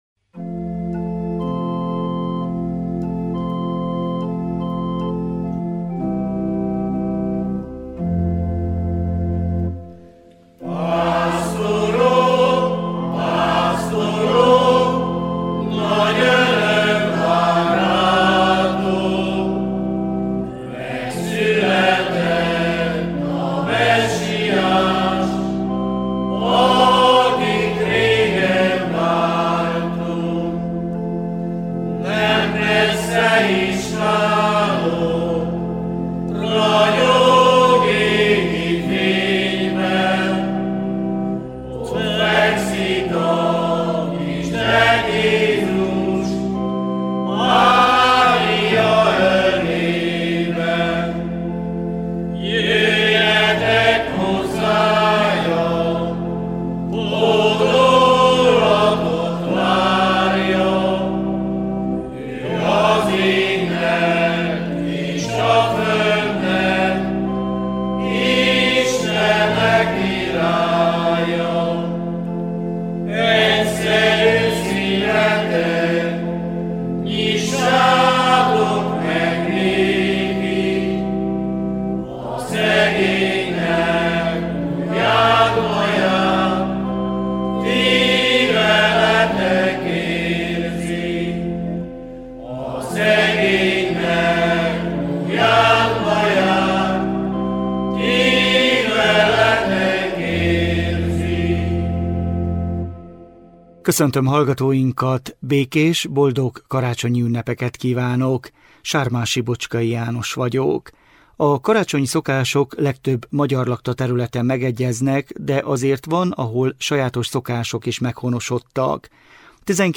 A karácsonyi szokások legtöbb magyarlakta területen megegyeznek, de azért van, ahol sajátos szokások is meghonosodtak. Ebben a műsorban 2013-ban, 2015-ben, 2021-ben és 2024-ben készült interjúkban kapunk képet kisebb és nagyobb közösségek karácsonyi szokásairól.